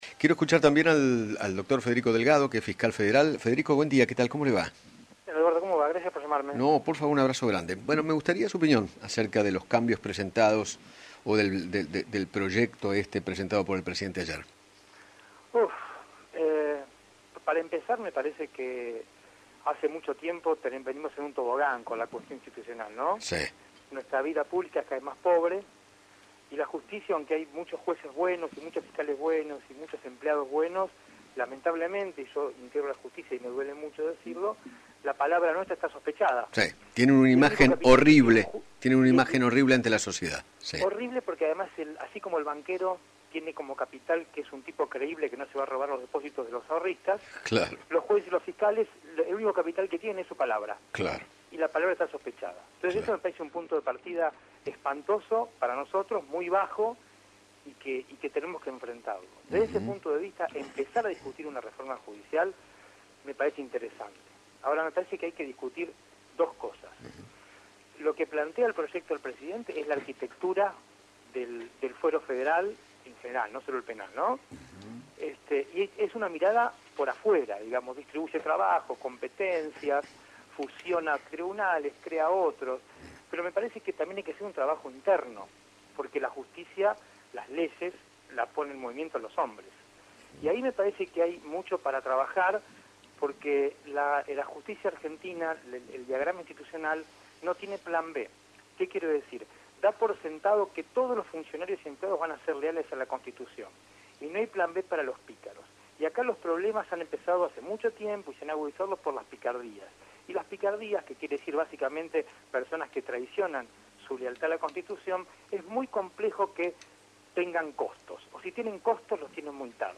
Federico Delgado, Fiscal Federal, dialogó con Eduardo Feinmann sobre la reforma judicial que pretende realizar Alberto Fernández y sostuvo que “tenemos una gran oportunidad y prefiero ver la mitad del vaso lleno con respecto a este proyecto”.